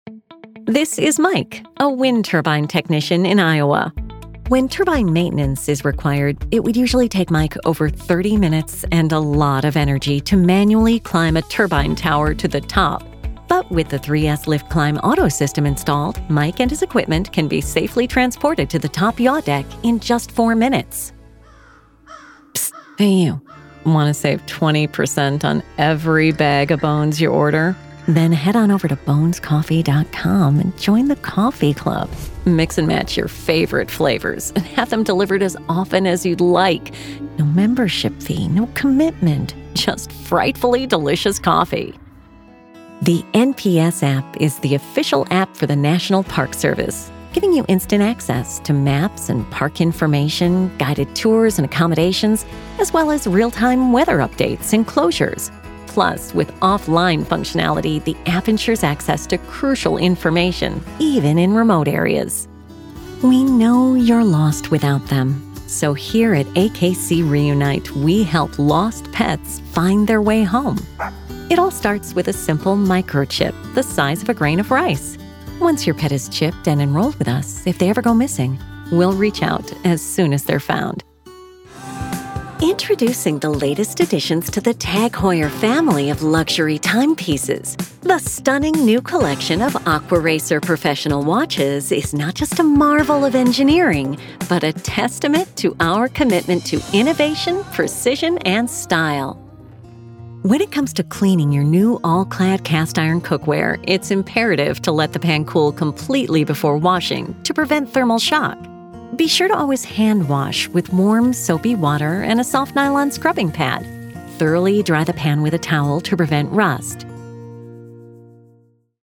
English (American)
Friendly, Corporate, Natural, Accessible, Warm
Corporate